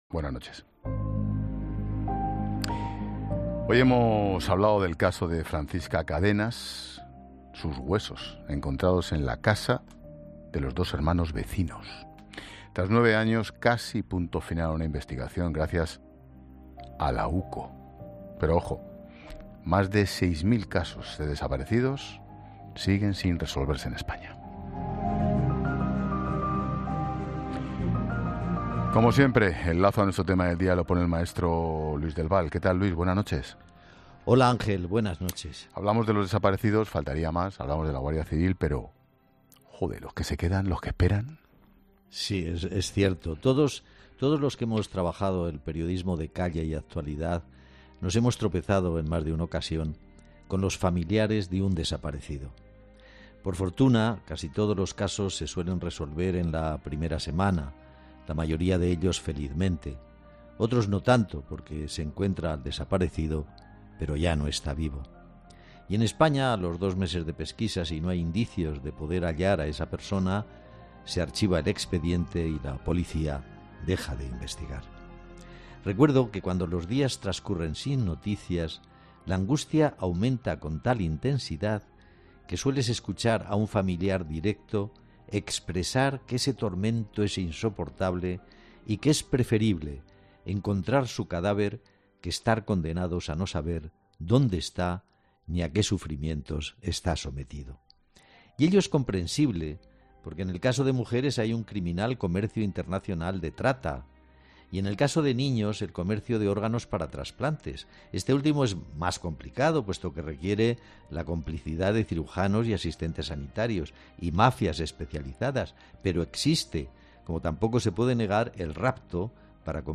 Sobre este doloroso tema ha puesto el foco el maestro Luis del Val en 'La Linterna' de COPE, en su charla con Ángel Expósito.